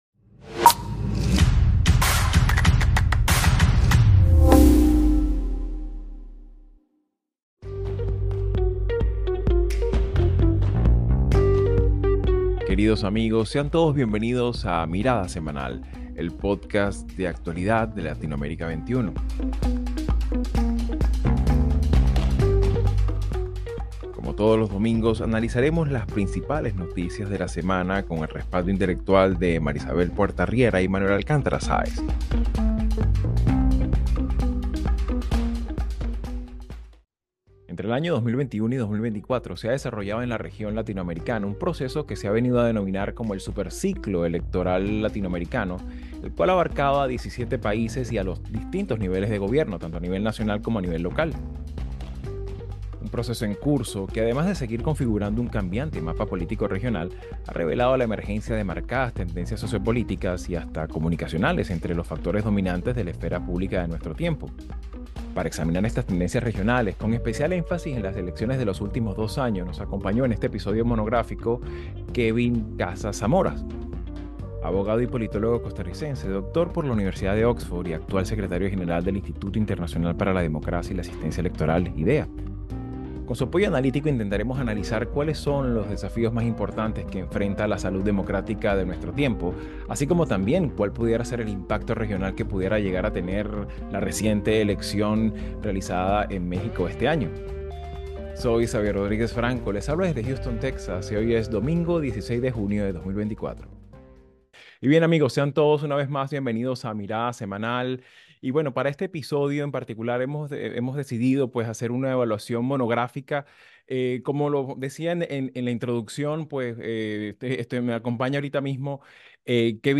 Elecciones y democracia en América Latina. Entrevista a Kevin Casas-Zamora